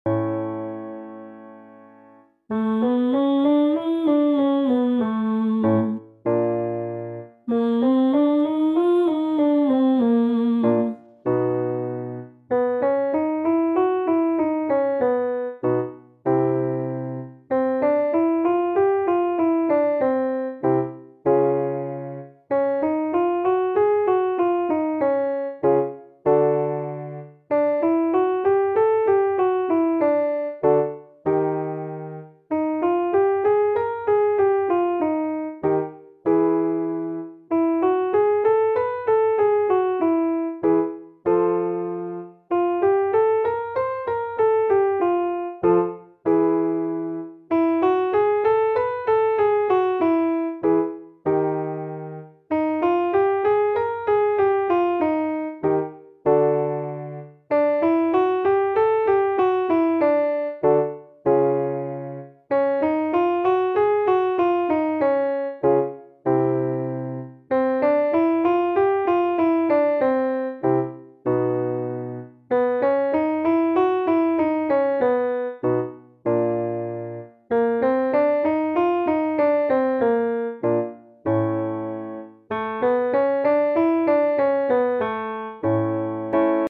New 5 Minute Vocal Warm Up Routine.